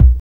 SOFT SWEET.wav